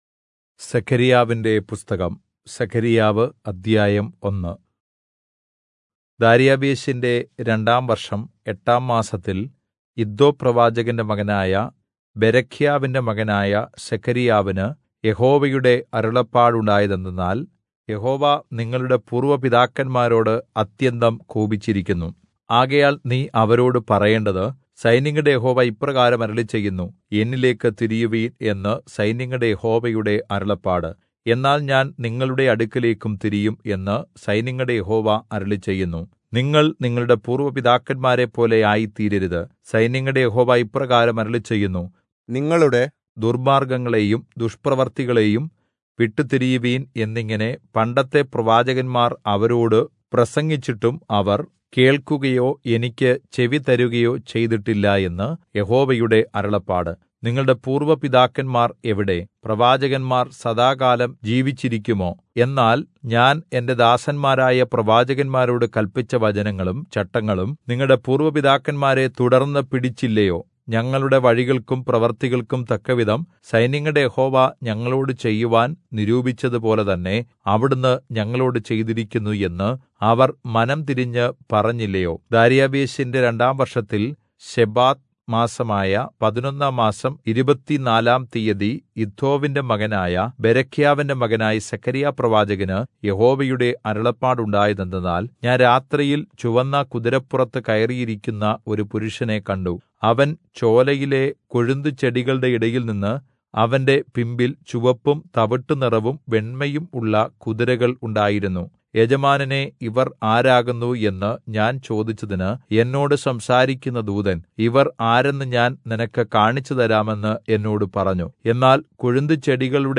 Malayalam Audio Bible - Zechariah All in Irvml bible version